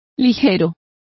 Complete with pronunciation of the translation of flippant.